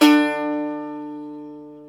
ETH XSAZ  D4.wav